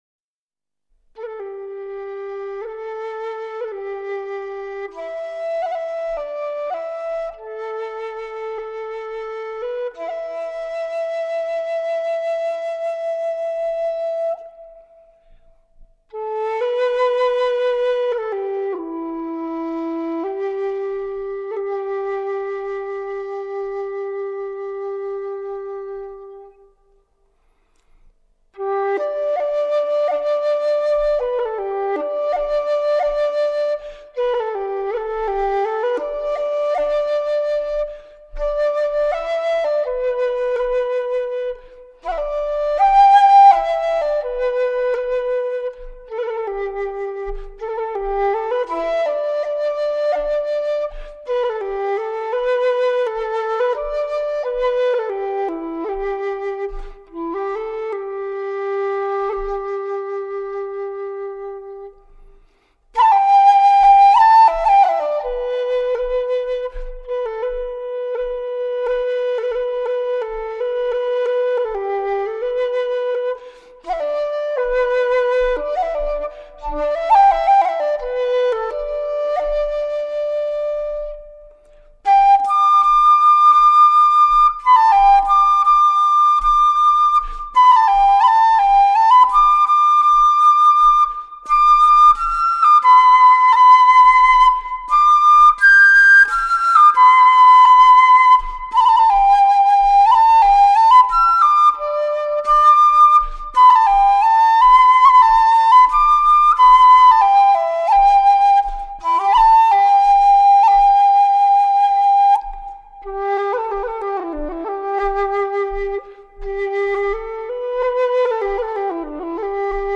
尽享醇厚圓润之音色、典雅华丽之乐章